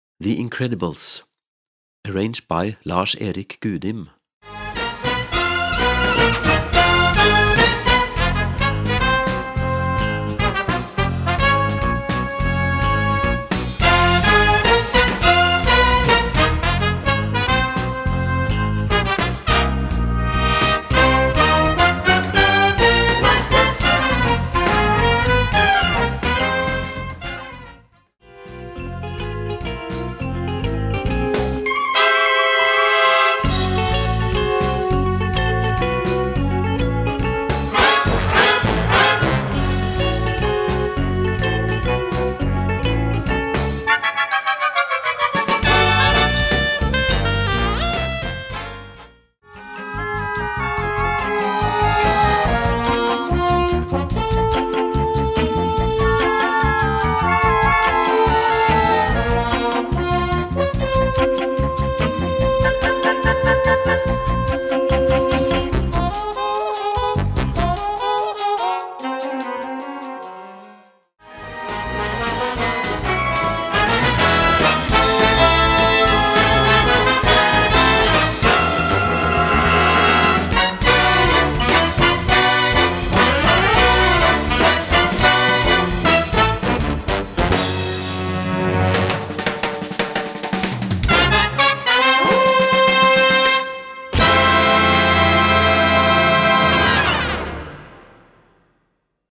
Gattung: Music from the movie
Besetzung: Blasorchester
music with high energy and a lot of wind players fits well.